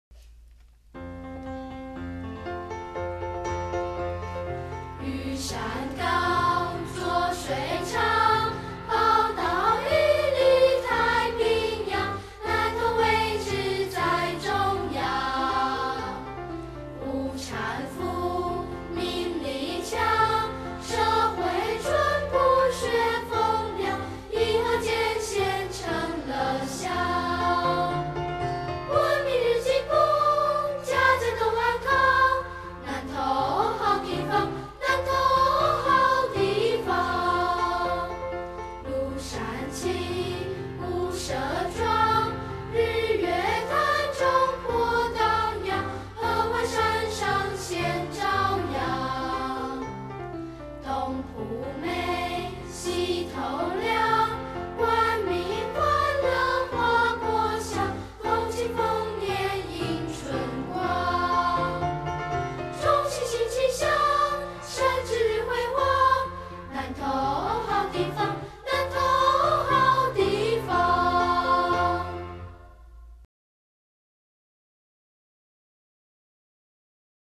說到意義重大的歌 不得不提的大概是這首吧 南投縣歌 國語合唱版/光華國小合唱團（from 南投縣政府官網）